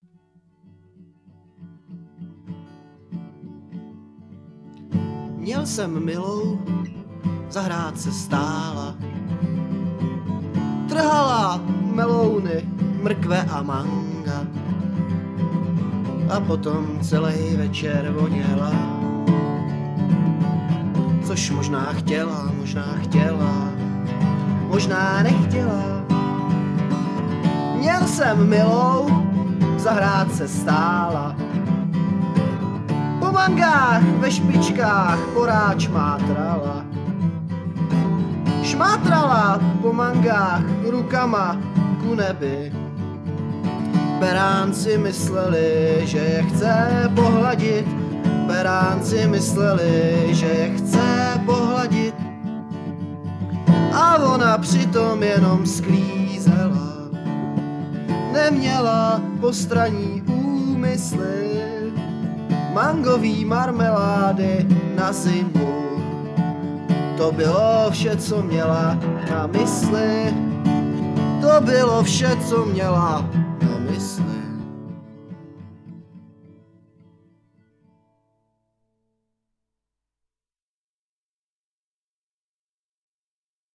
kbd, harm, dr
g, sitar, harm